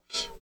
85 MOD CYM-R.wav